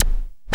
Closed Hats
07_Perc_16_SP.wav